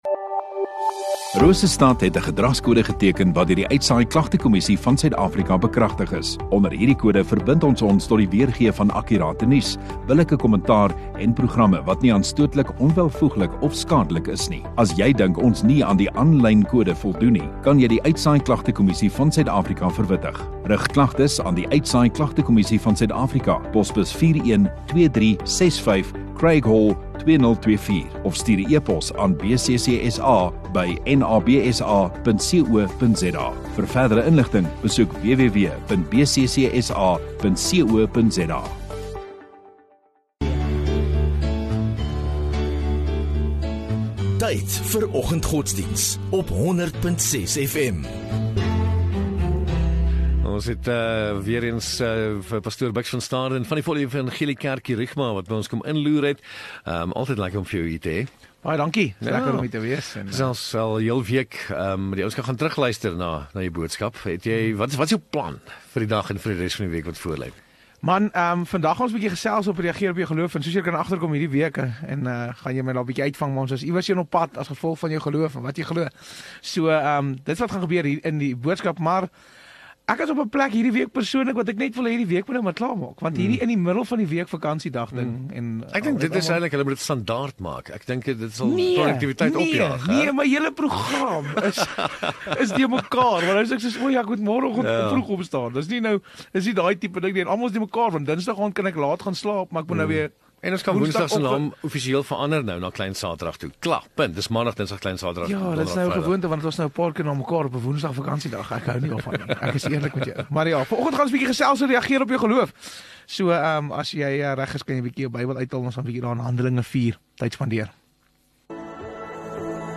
30 May Donderdag Oggenddiens